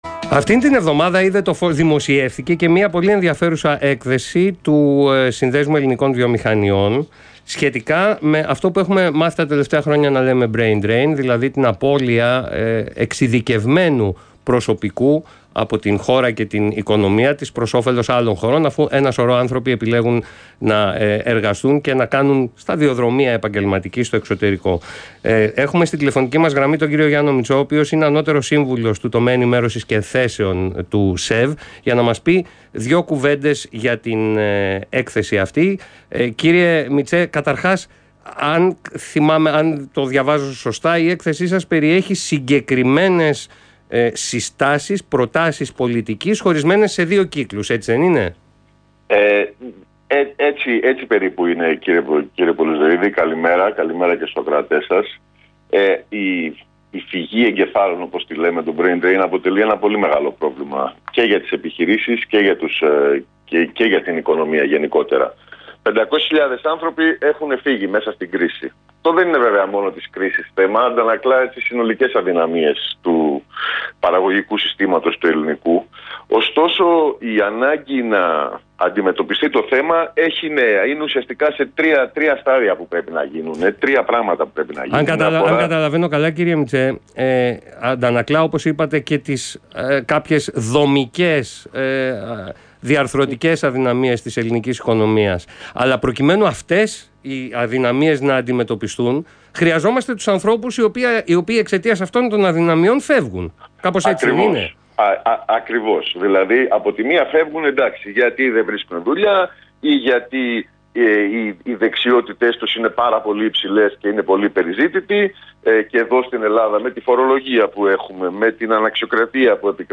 Συνέντευξη
στον Ρ/Σ REAL FM